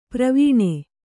♪ pravīṇe